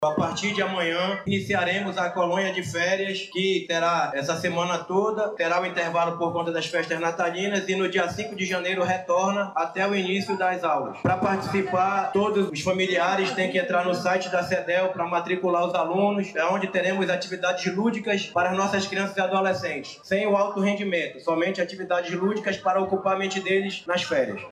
Ainda segundo o titular da Sedel, a Colônia de Férias da Vila Olímpica vai iniciar nesta segunda-feira 15/12, terá um recesso para as festas de fim de ano, com retorno em janeiro.